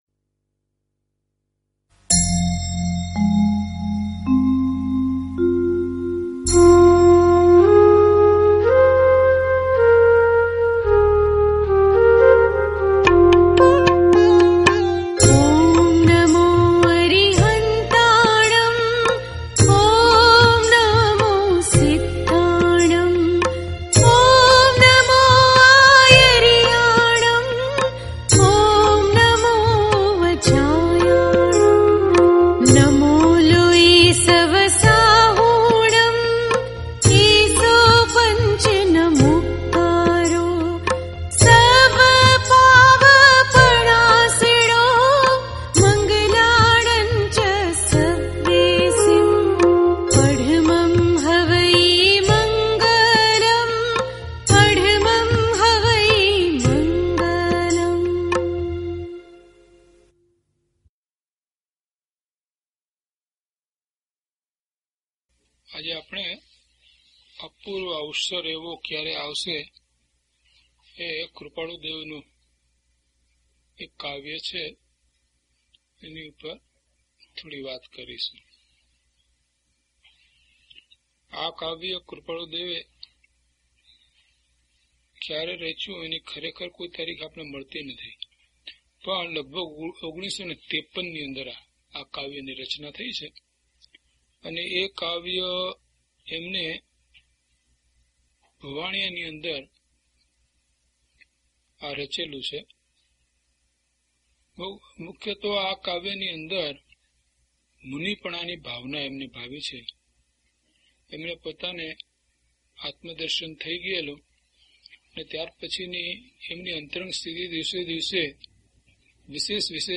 DHP006 Apurva Avasar Gatha 1 to 4 - Pravachan.mp3